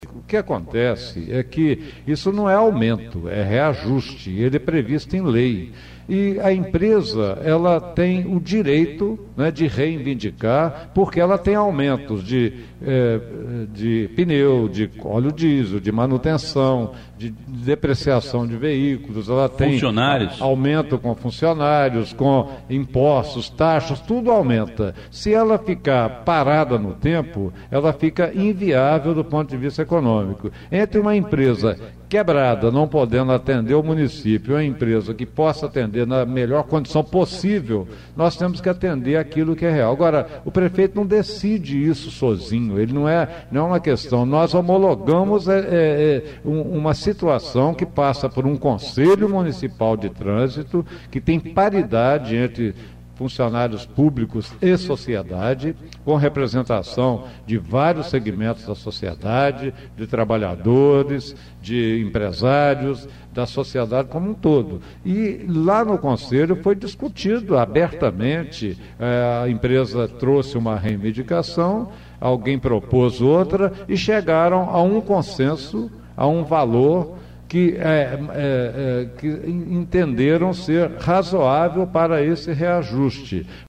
Em entrevista á Rádio Educadora – Prefeito Edson Teixeira Filho explicou o reajuste da tarifa de transporte coletivo de Ubá